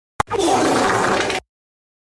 Fart Sounds Sound Effect Download: Instant Soundboard Button